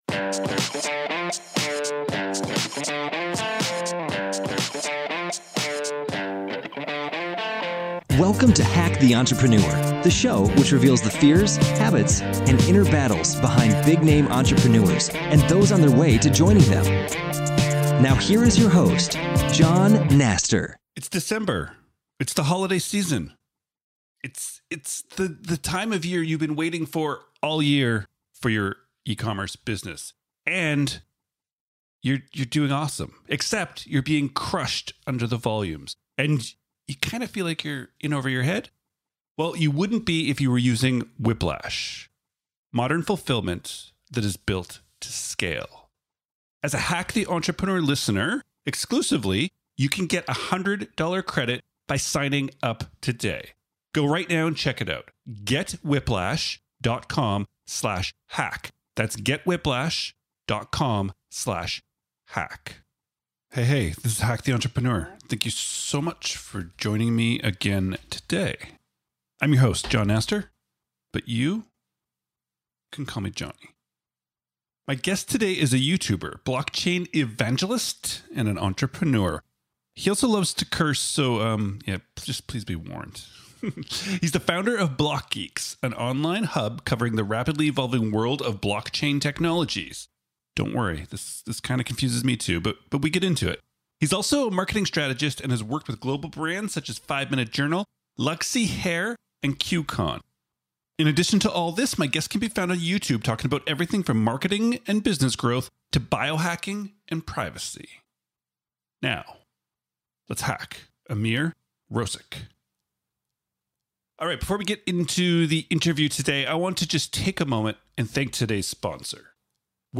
He also loves to curse, so please be warned.